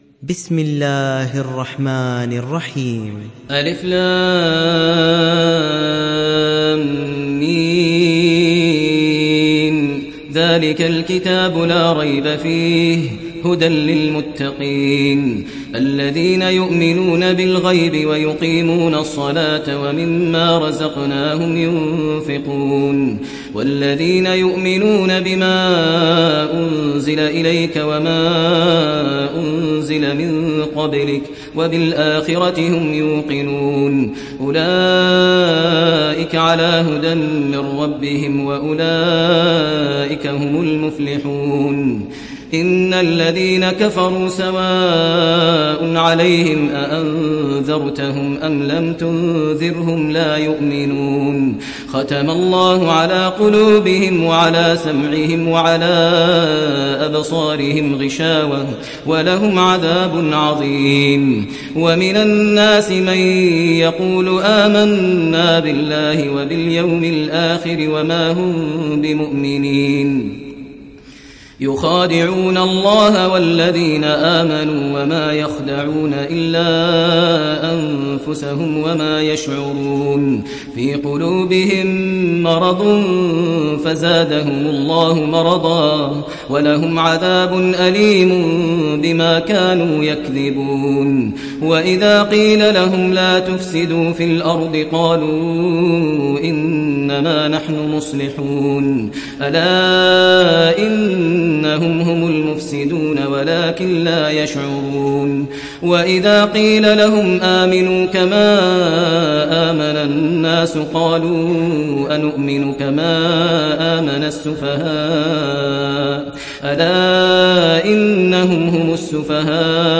تحميل سورة البقرة mp3 بصوت ماهر المعيقلي برواية حفص عن عاصم, تحميل استماع القرآن الكريم على الجوال mp3 كاملا بروابط مباشرة وسريعة